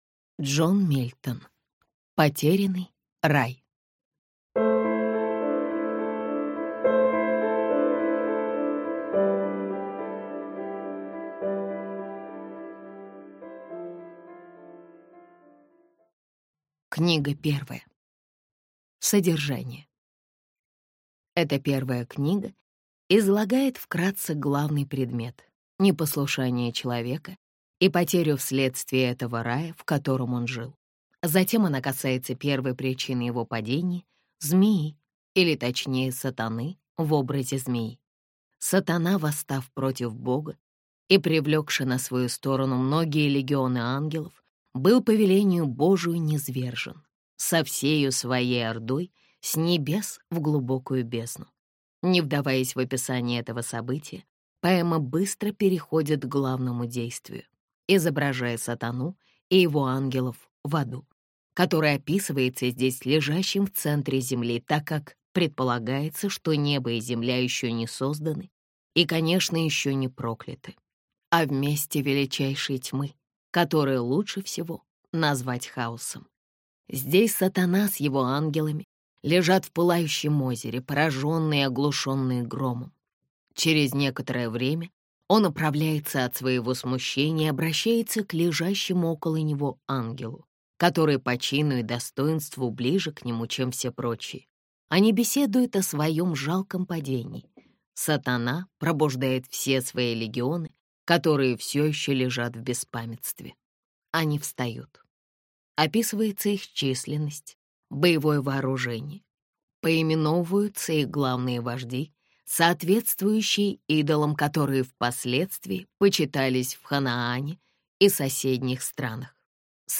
Аудиокнига Потерянный рай | Библиотека аудиокниг